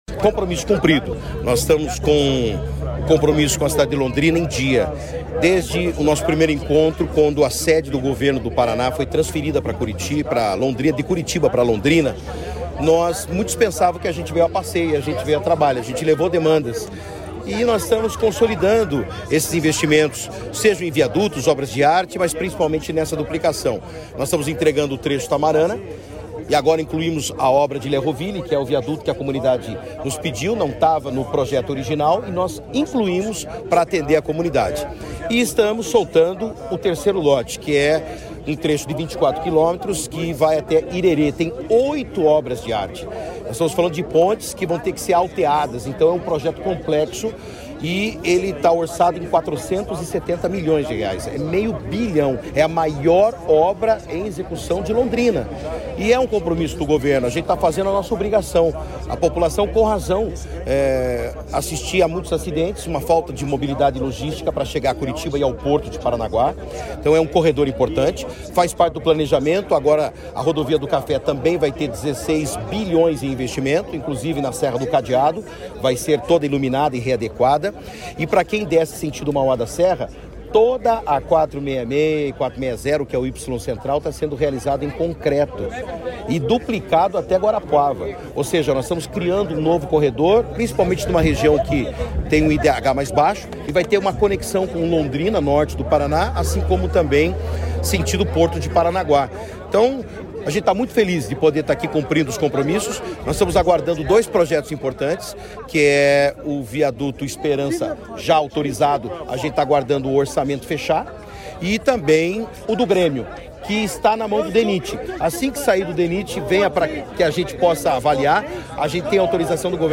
Sonora do secretário de Infraestrutura e Logística, Sandro Alex, sobre a duplicação da PR-445 de Mauá da Serra a Lerroville